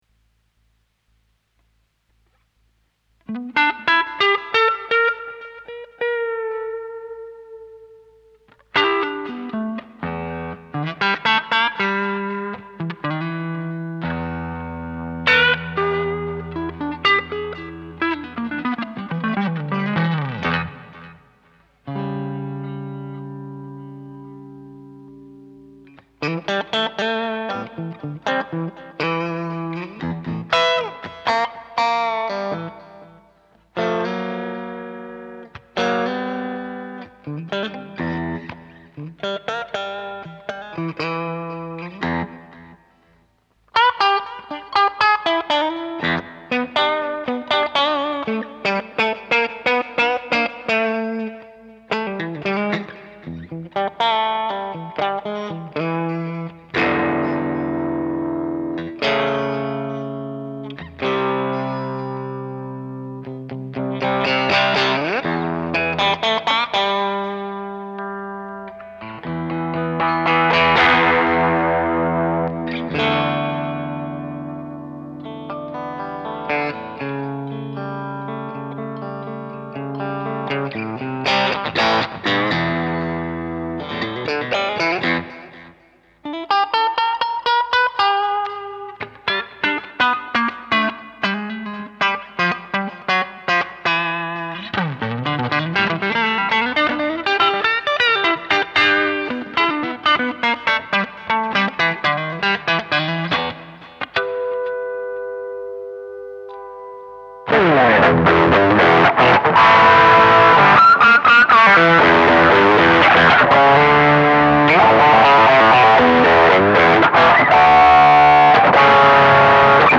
Great tone, very versatile guitar.
sample (Marshall JVM, Transducer, some delay and reverb):